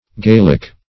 gaelic.mp3